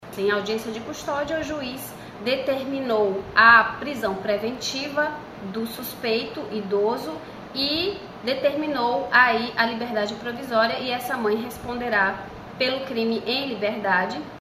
SONORA02_DELEGADA.mp3